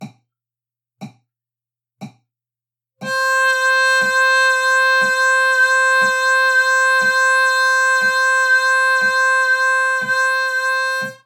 Subdividing to two oscillations each second (2Hz) is an improvement, but it is still far from ideal.
Here’s what it all sounds like using 4D on a Bb harp. Each clip uses a metronome set to 60bpm and has a count in of three beats..
2Hz
Vibrato-2Hz-i.mp3